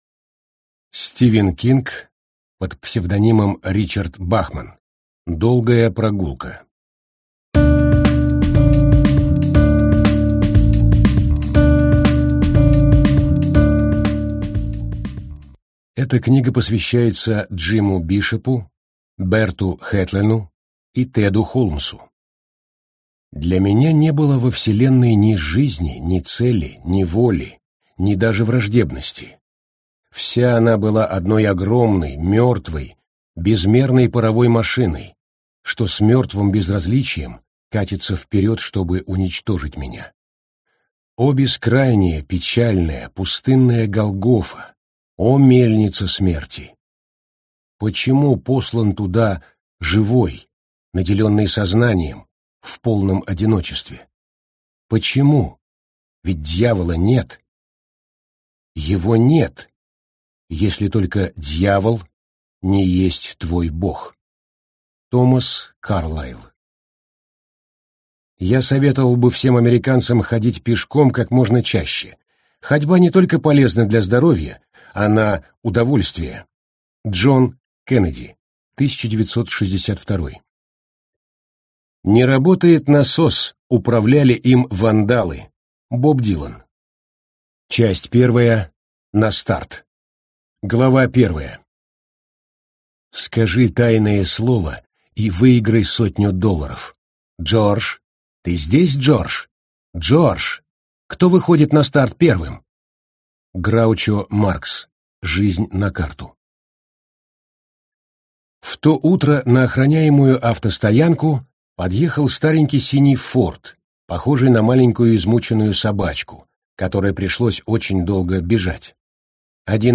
Аудиокнига Долгая прогулка - купить, скачать и слушать онлайн | КнигоПоиск